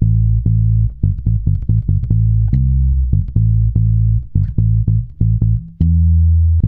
-MM RAGGA A.wav